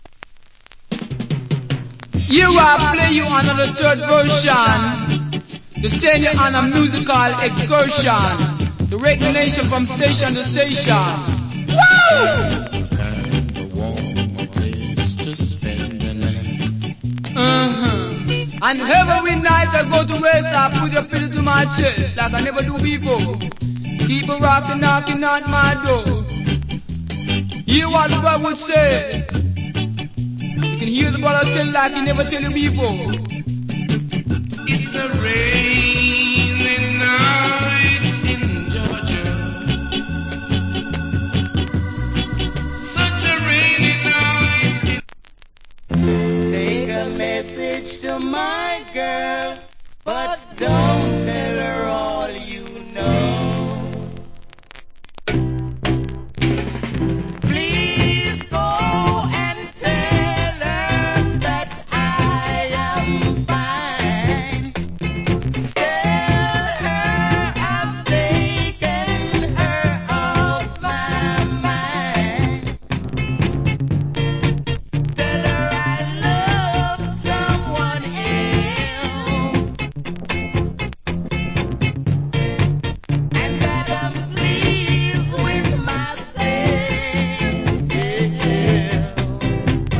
Good Early Reggae Vocal.